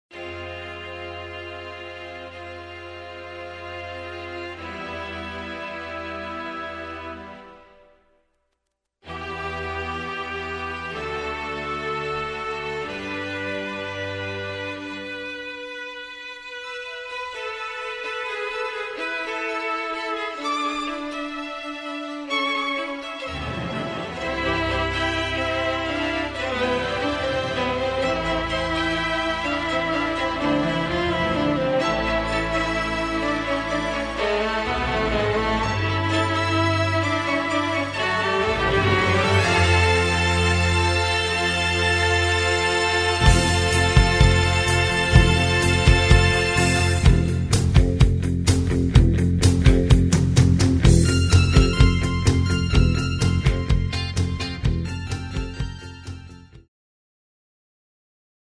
Karaoke Soundtracks.